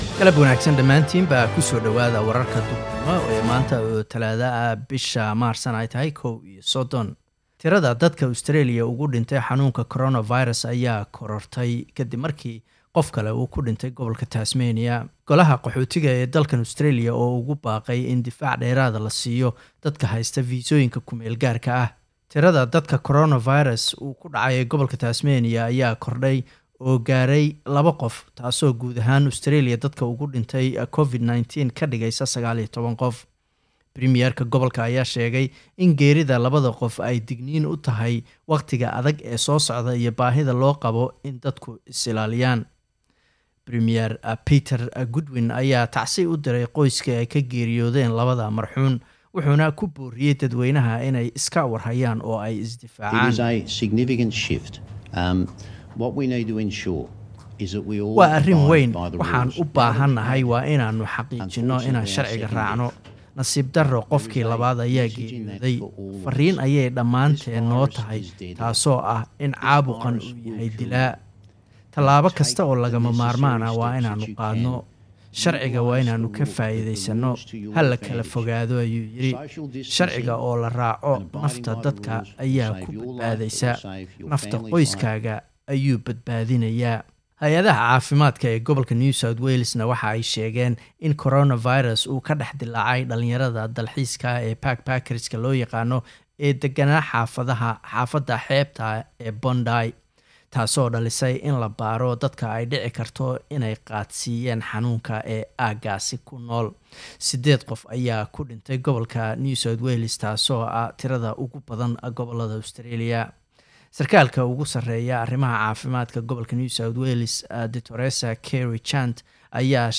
Waa wararkii duhurnimo oo dhamaan aan kaga hadkayno xanuunka COVID-19
midday_news.mp3